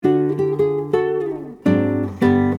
The Art of Arranging and Performing for Solo Guitar